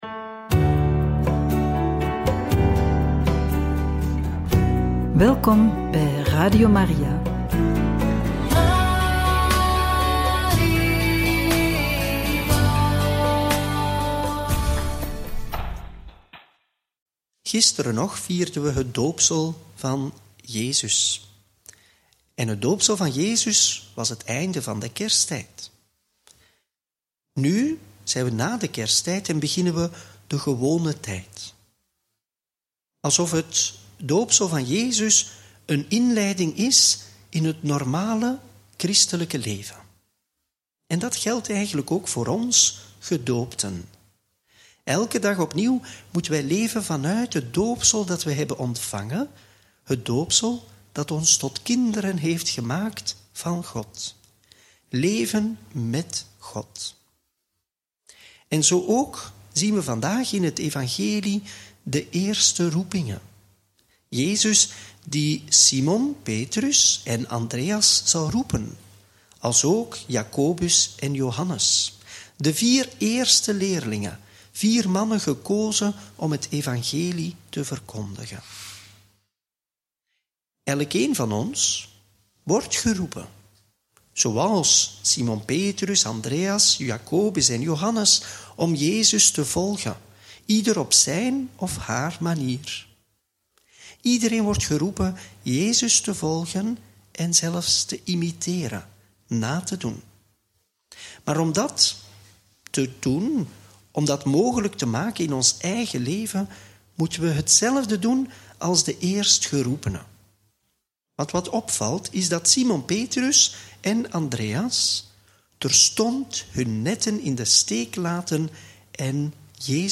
Evangelie